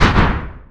EXPLOSION Short Smooth Brighter Kickback (mono).wav